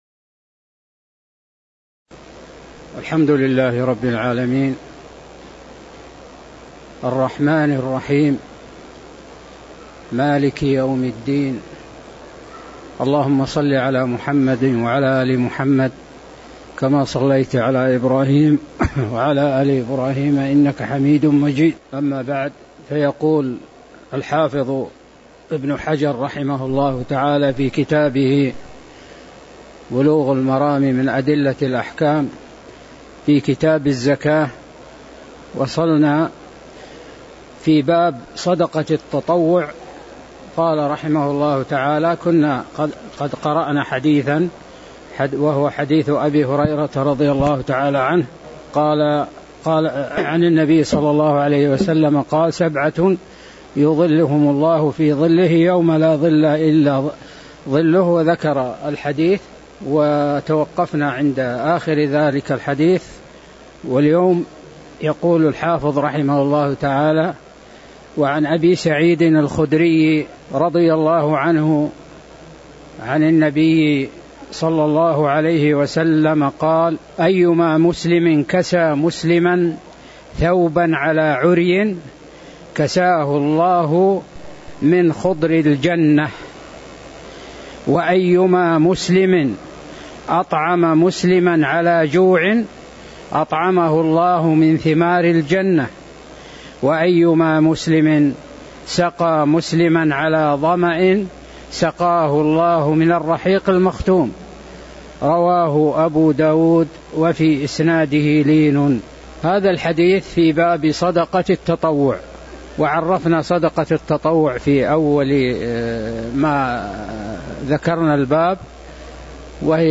تاريخ النشر ٧ صفر ١٤٤٠ هـ المكان: المسجد النبوي الشيخ